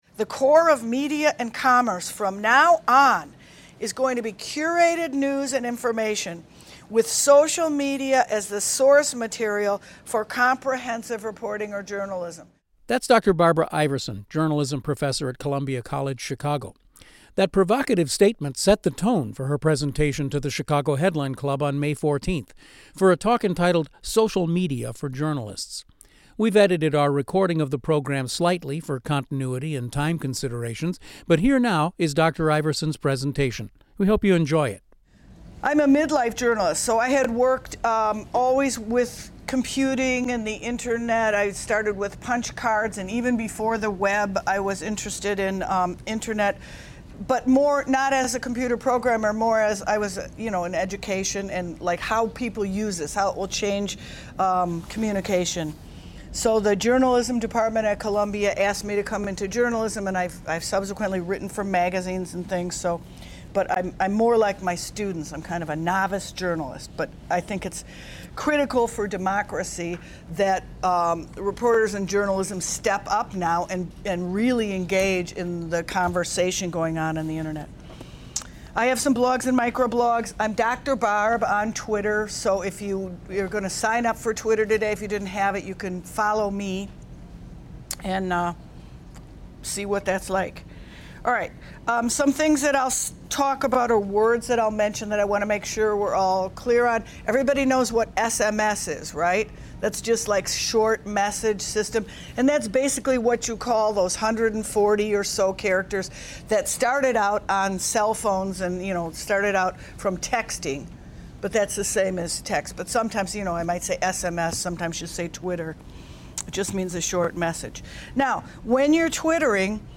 An audio recording of the presentation can be heard or downloaded here: